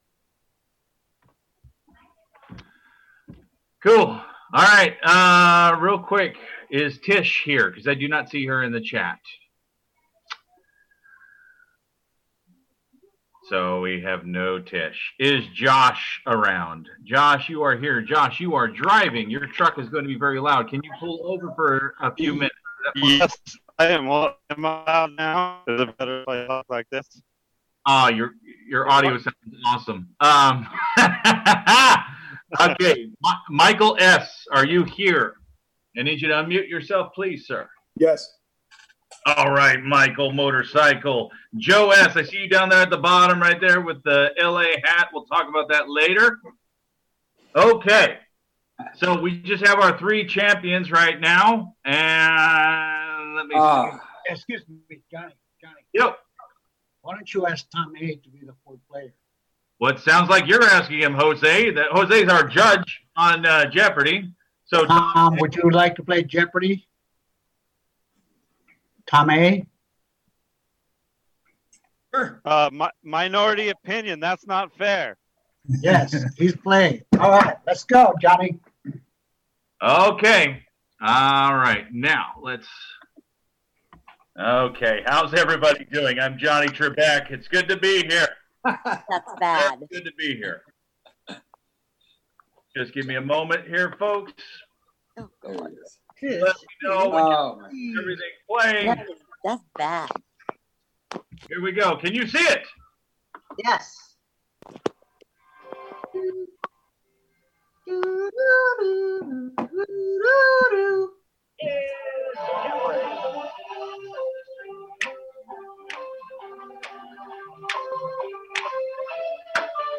Encore Audio Archives - 12 Step Recovery CNIA DISTRICT 40 41 42 AND 43 TRADITIONS WORKSHOP